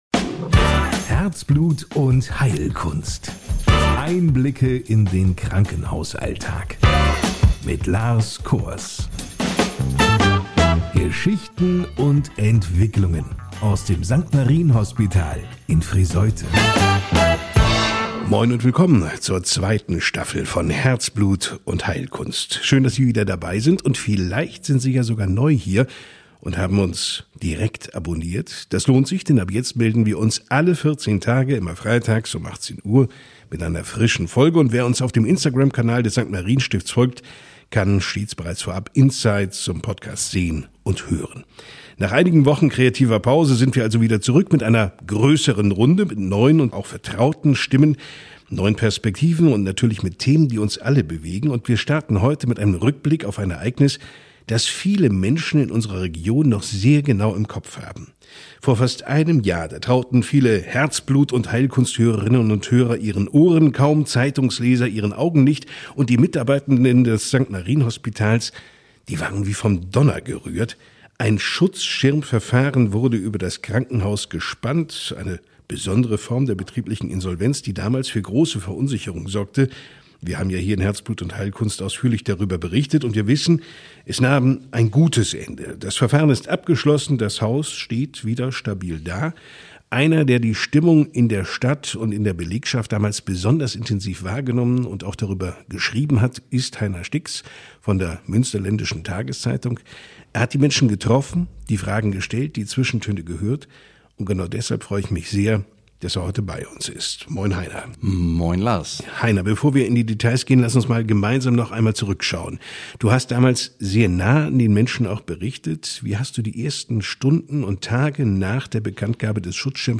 Nach einem Jahr im Krisenmodus sprechen Verantwortliche und Betroffene des St.-Marien-Hospitals Friesoythe offen über die Veränderungen, verbleibende Herausforderungen und die aktuelle Lage der Klinik. Die Folge bietet ehrliche Einblicke in Erfolge, Sorgen und die Stimmung im Haus.